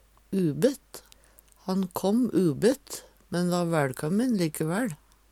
ubett - Numedalsmål (en-US)